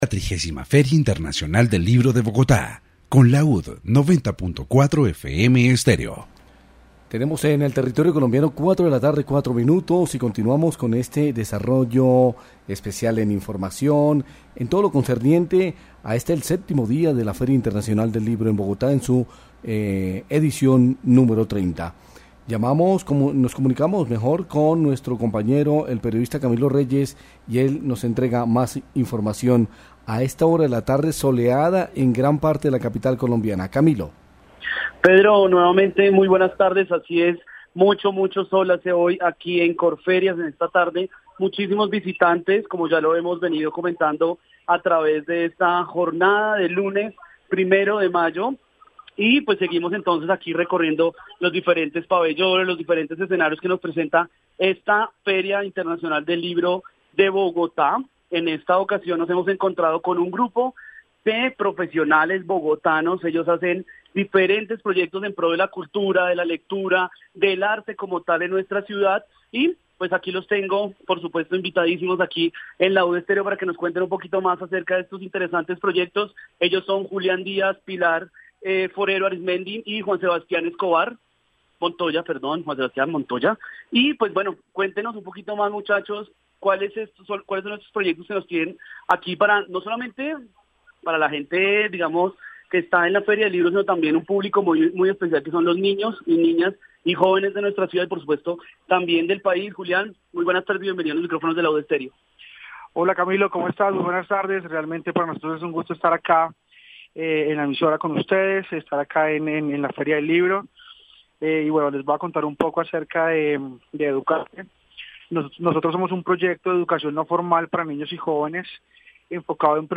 Feria del Libro 2017. Informe radial